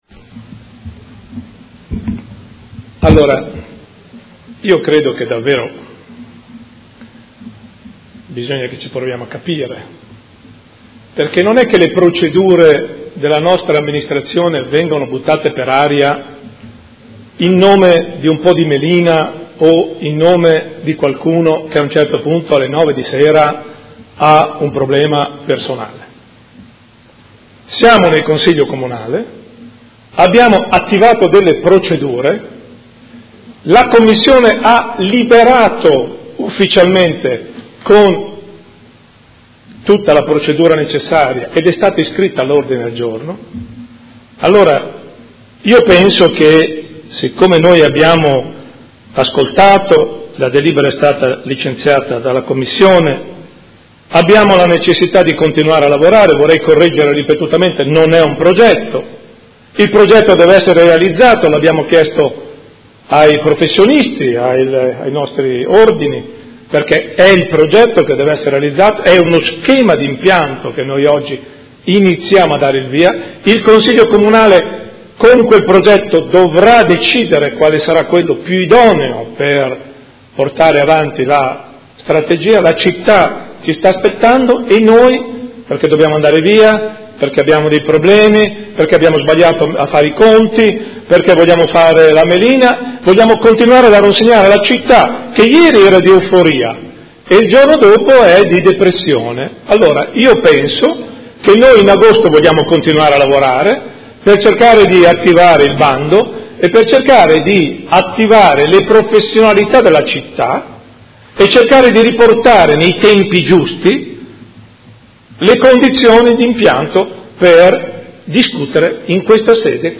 Gian Carlo Muzzarelli — Sito Audio Consiglio Comunale
Approvazione del Documento di indirizzo volto alla rigenerazione dell’ambito urbano di riferimento dell’area “Ex sede AMCM” Intervento contrario alla sospensiva